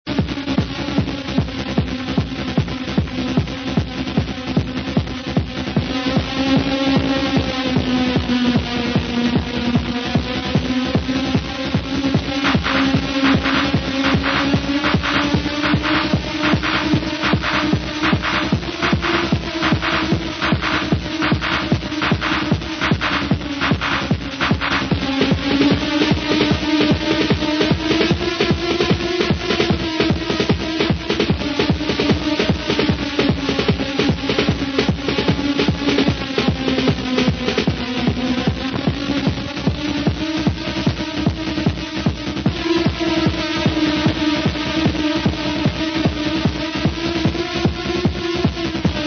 love this, totally messes with your mind